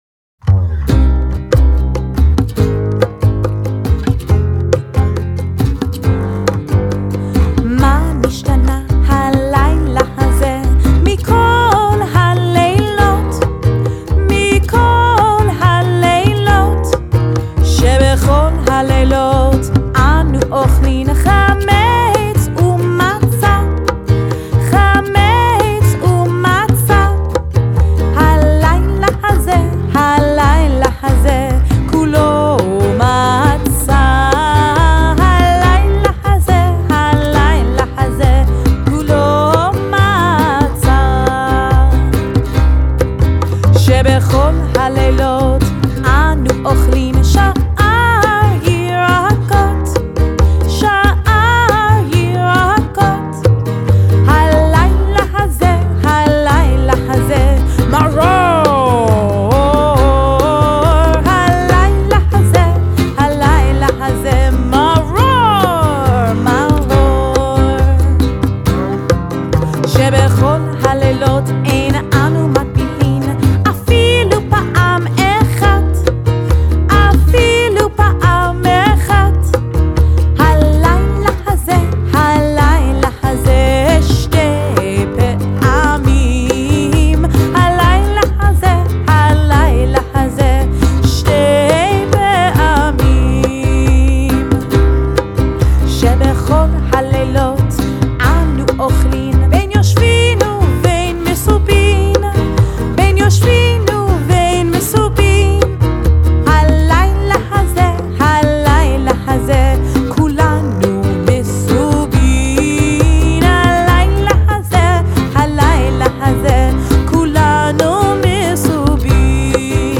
sing along